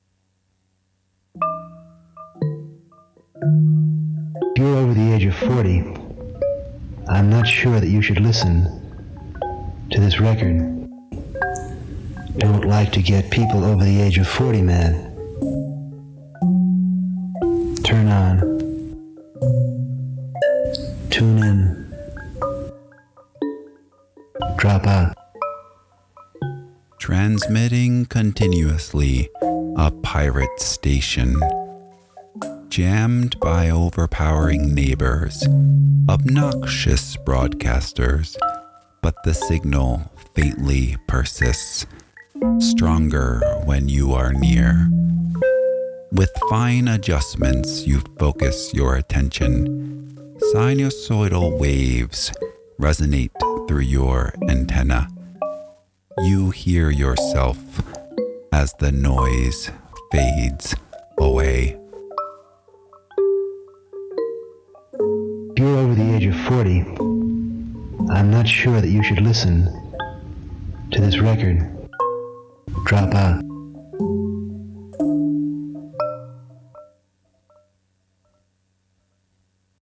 Here's a performance of this poem.